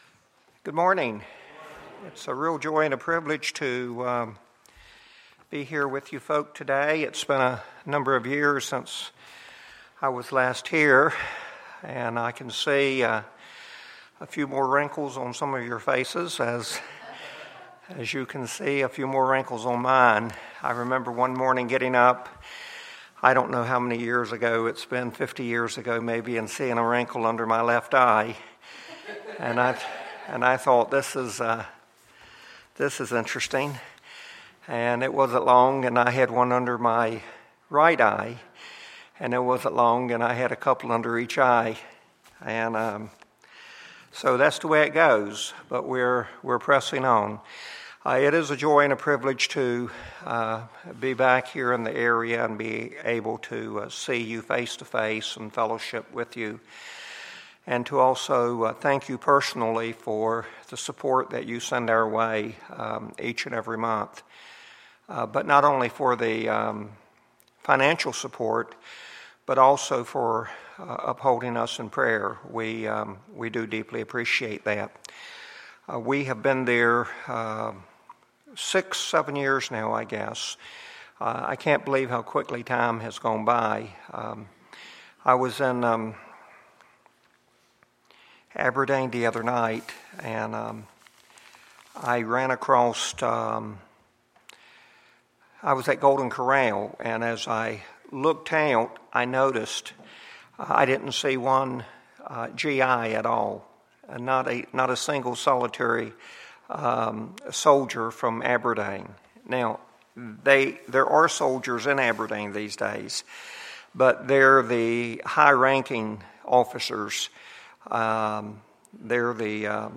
Sunday, September 21, 2014 – Sunday School